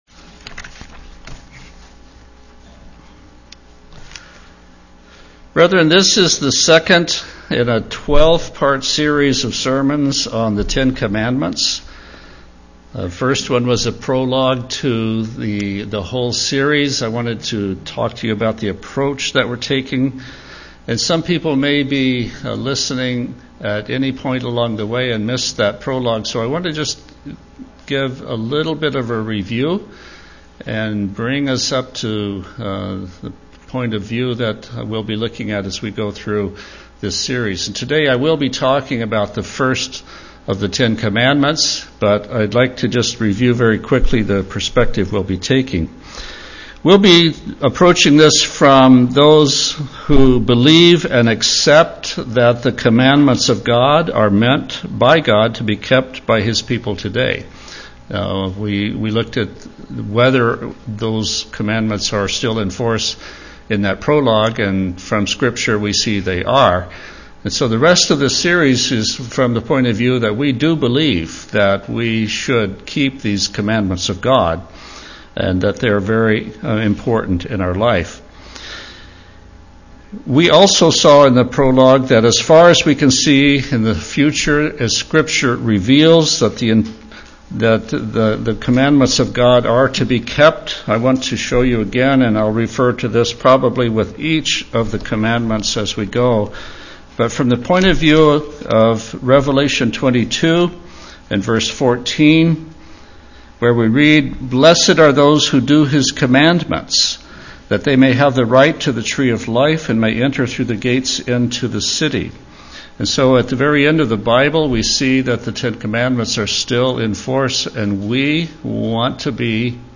Given in Olympia, WA